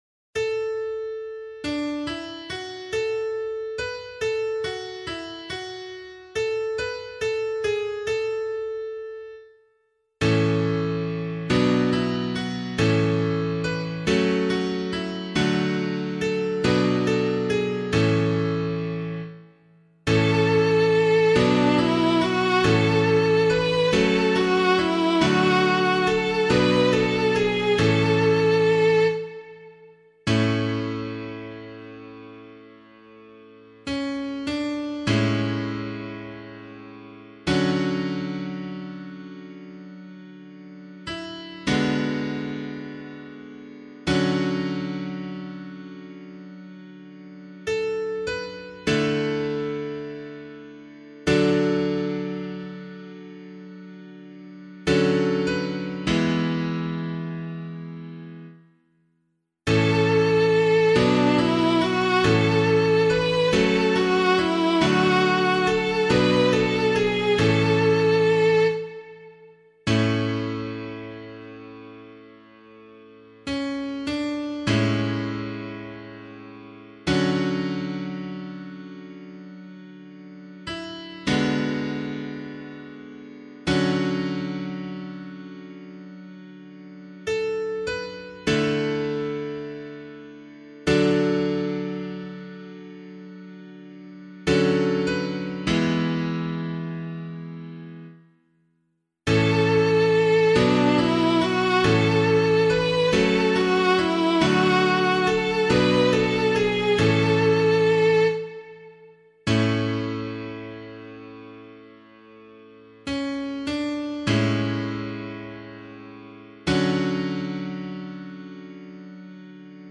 022 Easter Vigil Psalm 1A [Abbey - LiturgyShare + Meinrad 8] - piano.mp3